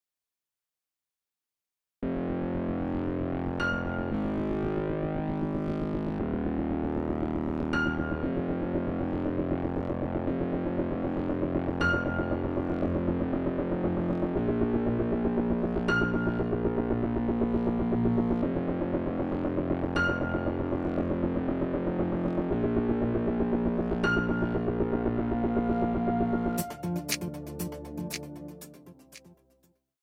Instrumental Solos Piccolo/Flute
Flute